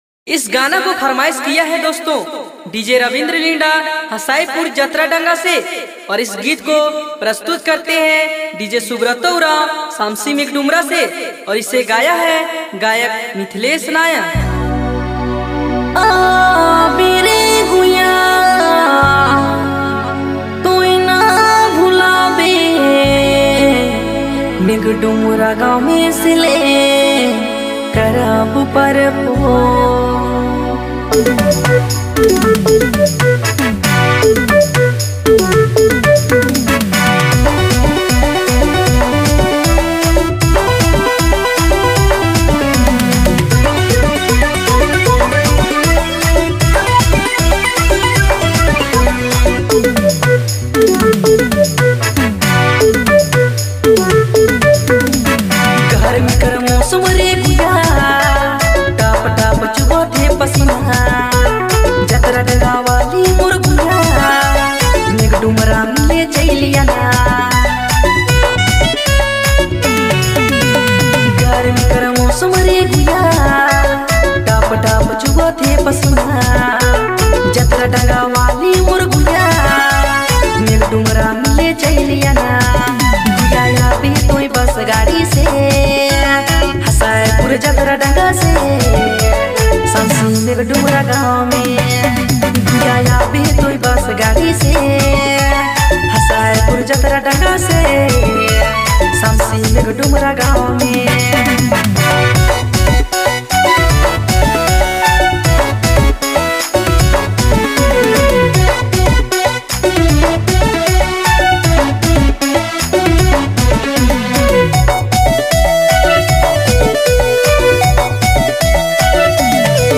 All Dj Remix